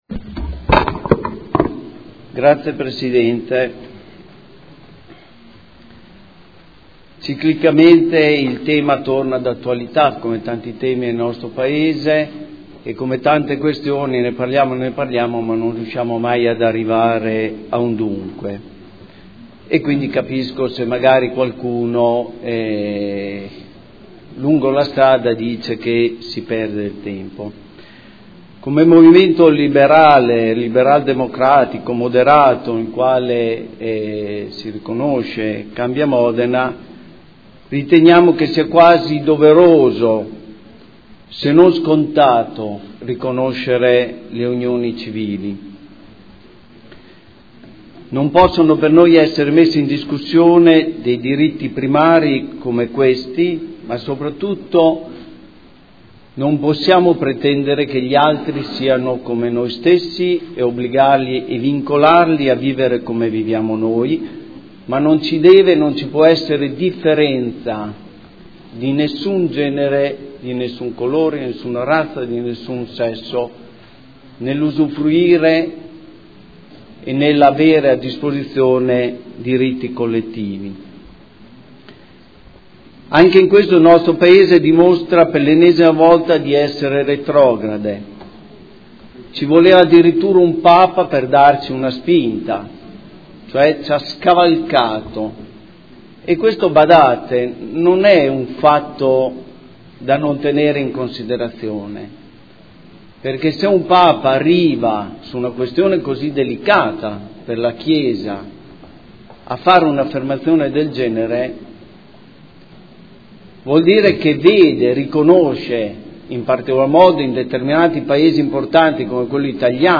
Seduta del 23/10/2014. Dibattito sulle mozioni presentate, inerenti la tematica del registro unioni civili